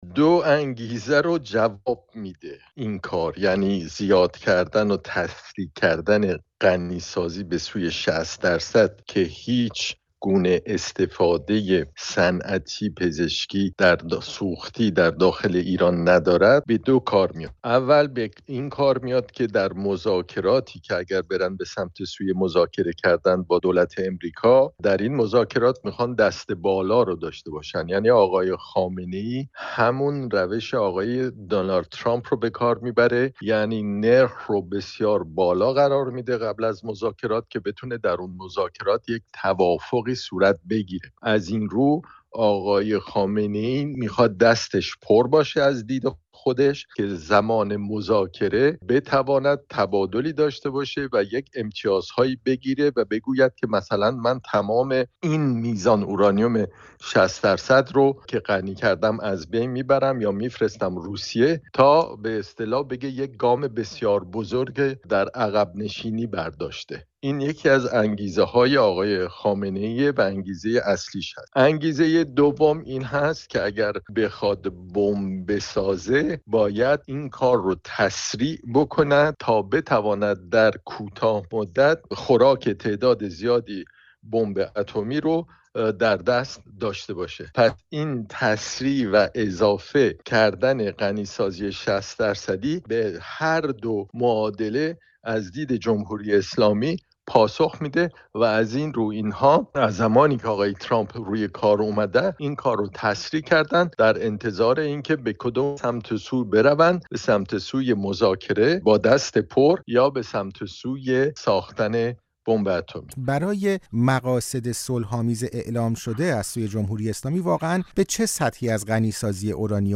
گفت‌گو